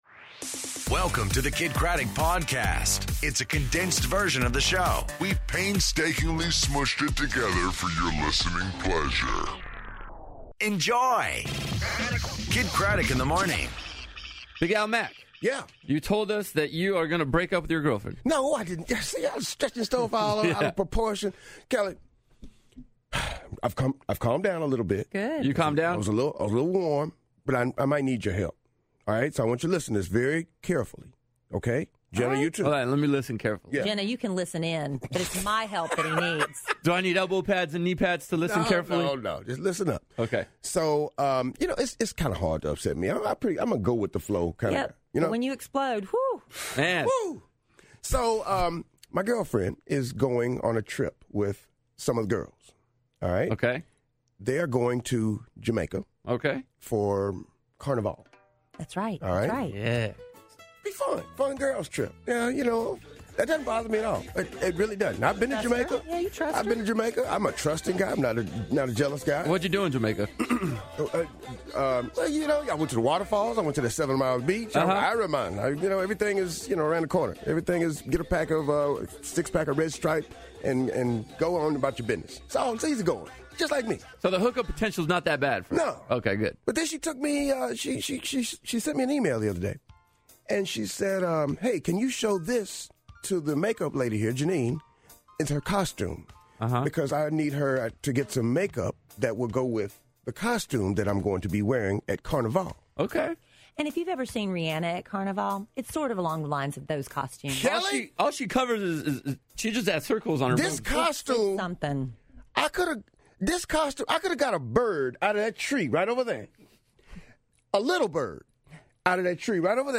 Josh Groban On The Phone